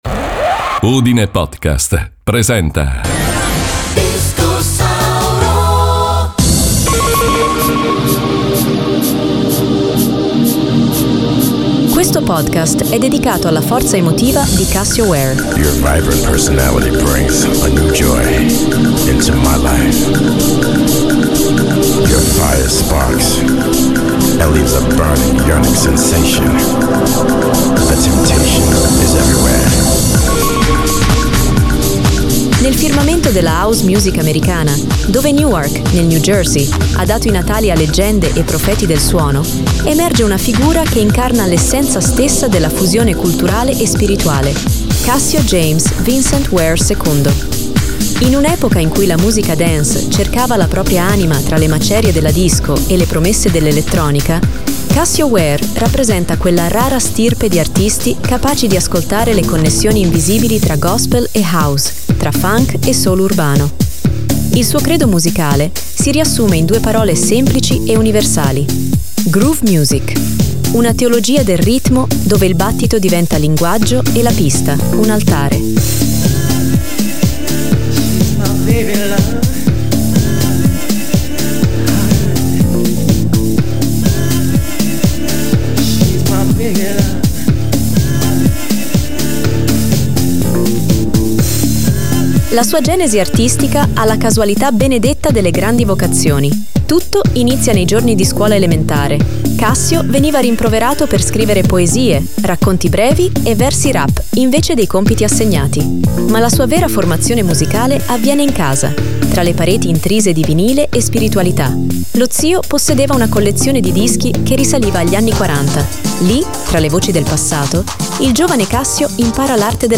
Il suo credo musicale si riassume in due parole semplici e universali: Groove Music.